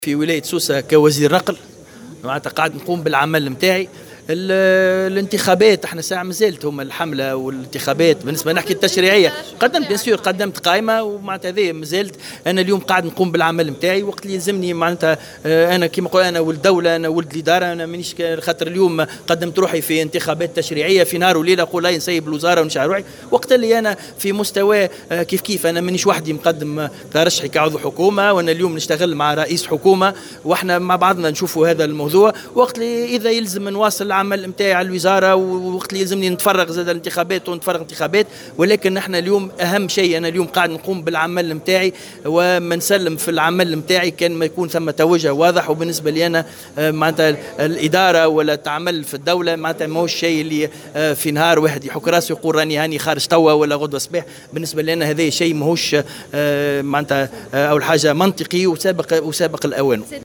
أكد وزير النقل هشام بن أحمد تقديم ترشّحه للانتخابات التشريعية 2019 ، مستدركا بالقول إنه ليس العضو الوحيد بالحكومة الذي قدم ترشحه لمثل هذه الانتخابات المقررة يوم 6 اكتوبر 2019. وتابع في تصريح لموفدة "الجوهرة أف أم" أنه حاليا بصدد القيام بعمله كوزير نقل وان المسألة سابقة لأوانها ولم يتقرر بعد التفرغ للانتخابات من عدمه، مؤكدا أولوية تسيير الوزارة التي يشرف عليها. وجاء ذلك على هامش اشرافه على امضاء عقد اقتناء أرض لفائدة شركة النقل بالساحل بولاية سوسة.